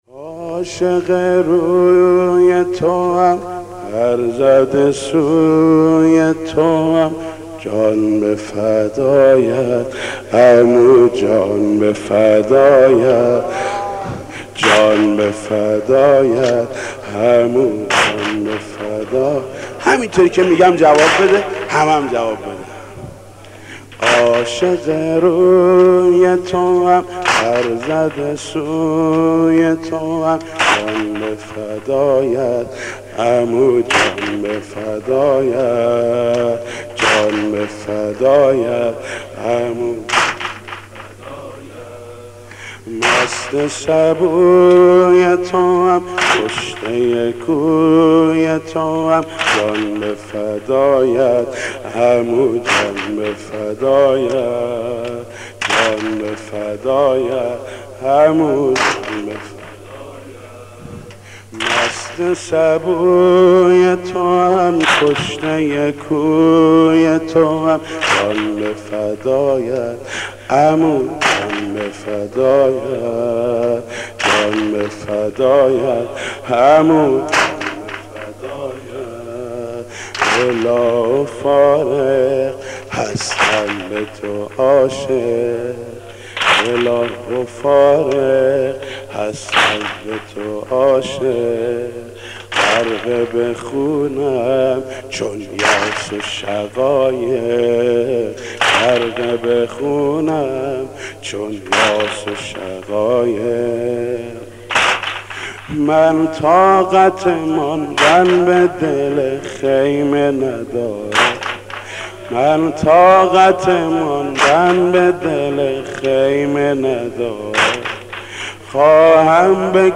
مداح
مناسبت : شب پنجم محرم
مداح : محمود کریمی